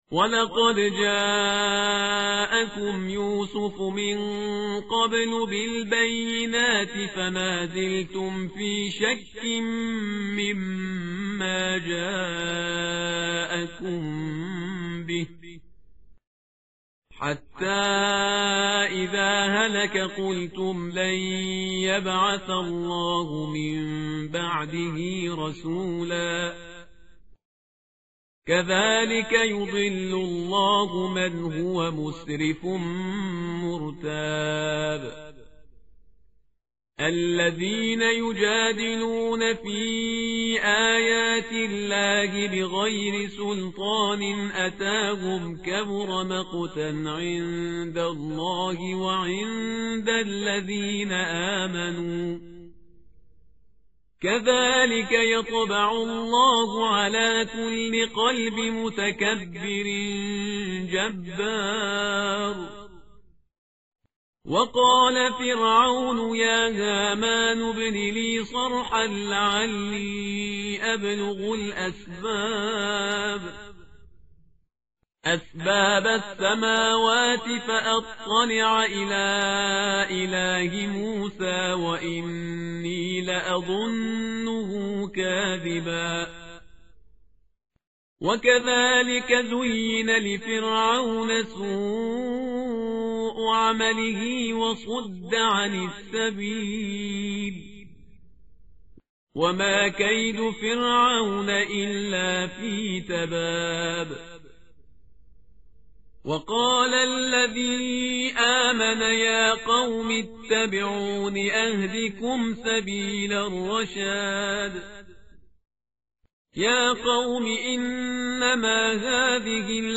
متن قرآن همراه باتلاوت قرآن و ترجمه
tartil_parhizgar_page_471.mp3